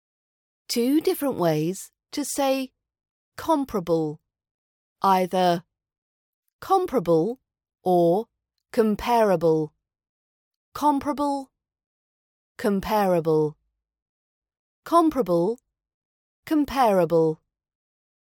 Comparing Compare with Comparable - Rp British accent pronunciation practice
Feel free to retweet or Share these RP British accent pronunciation audio files.